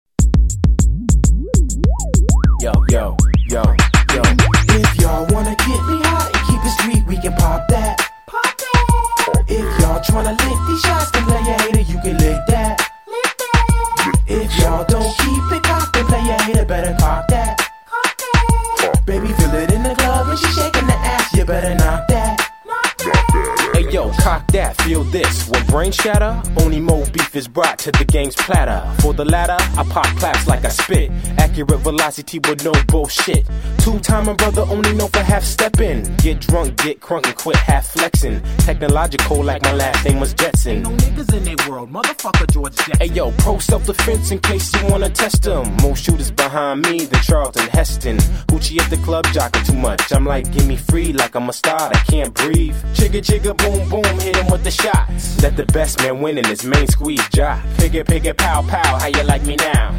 Genre: Rap & Hip-Hop.